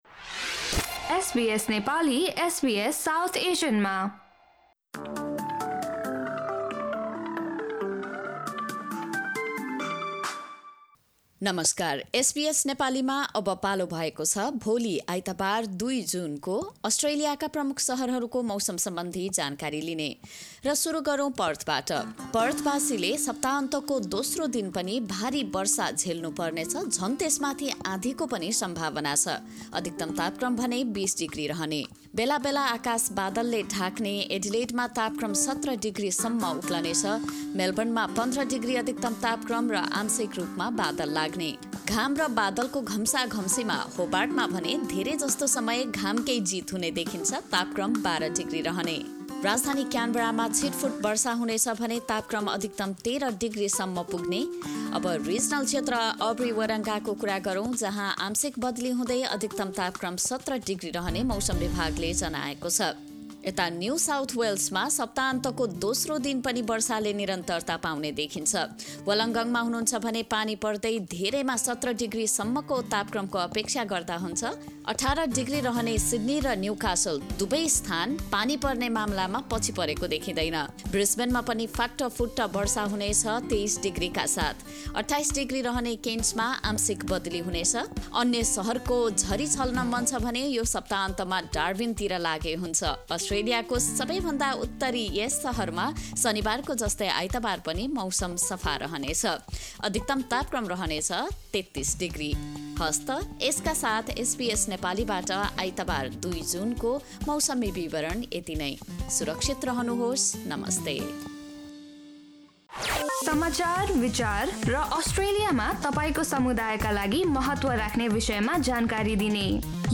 Australia Weather Update: Sunday, 2 June 2024